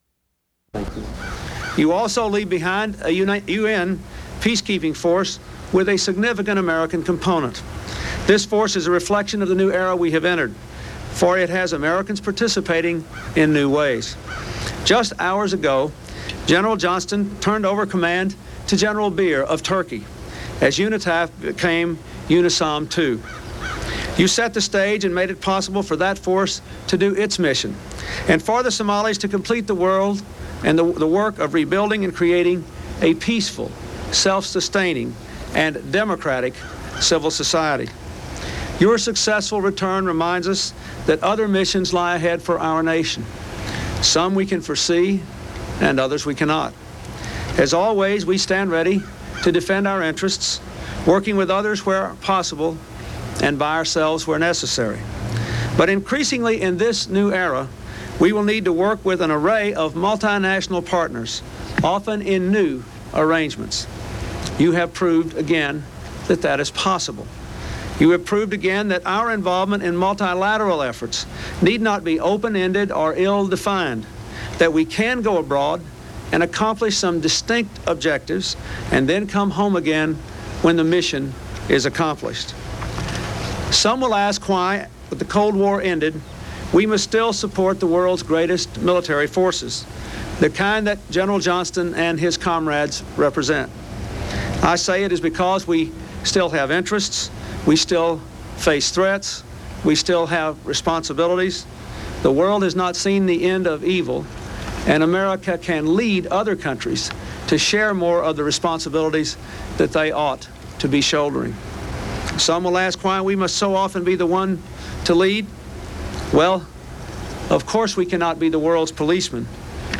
U.S. President Bill Clinton welcomes troops returning from Somalia